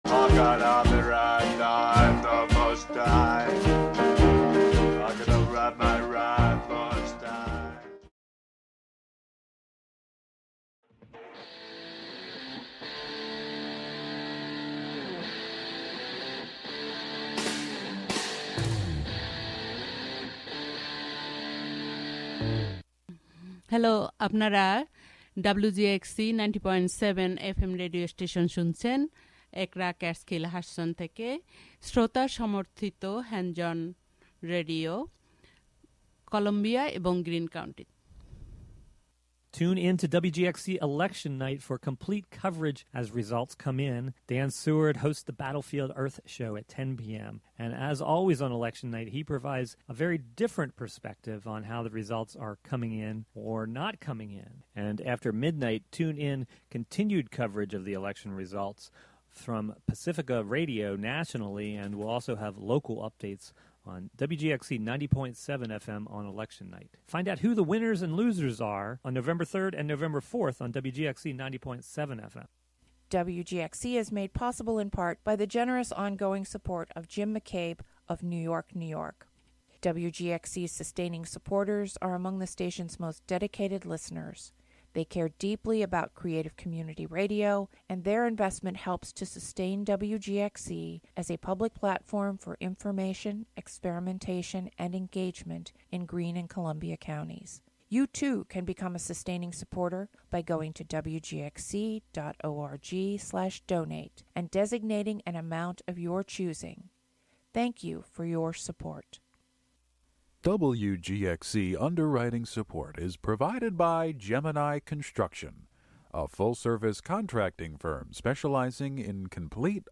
Thinking aloud around aerosolizations of voice — and what happens in the infrazone between mouth and mask. (Vocalise) First broadcast on Montez Press Radio as Tongue and Cheek- Ep24: Vocospherics - Saturday, October 31st, 2020 11AM-12:00 A radio series of proprioceptive exercises, interviews about practices of communication, and archival sound.
Presented monthly as a combination of live and prerecorded sessions.